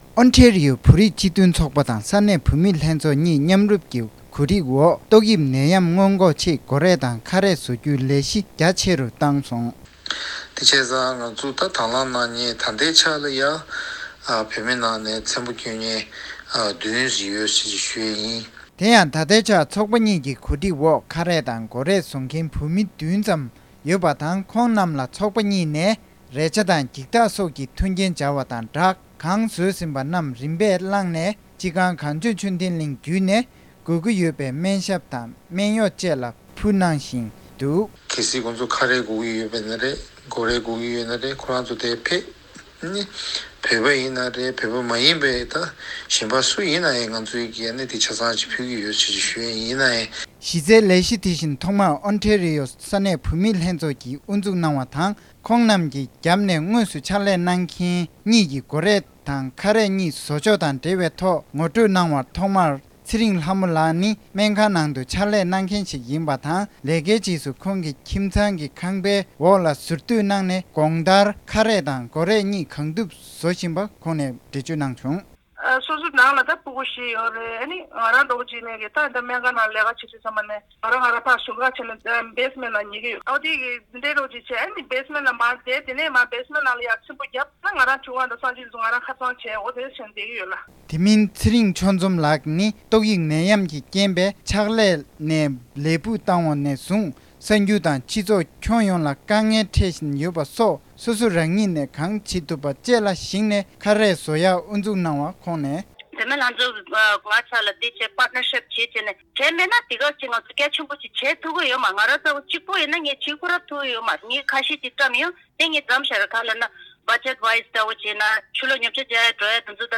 ས་གནས་ནས་བཏང་བའི་གནས་ཚུལ་ལ་གསན་རོགས།།